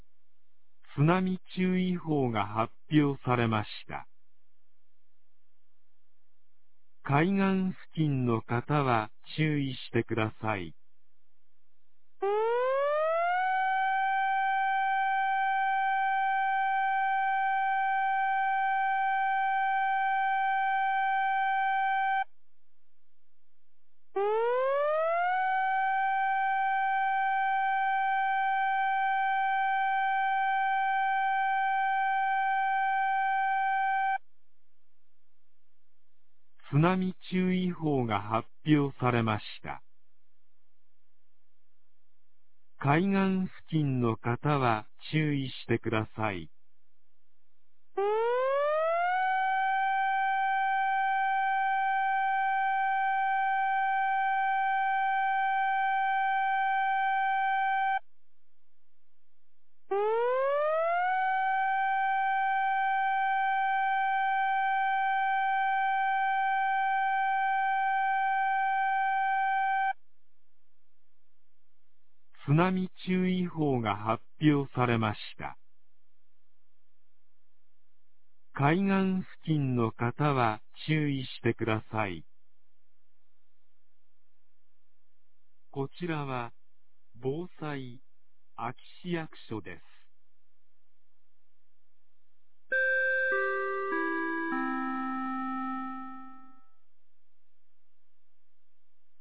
2025年07月30日 09時42分に、安芸市より全地区へ放送がありました。
放送音声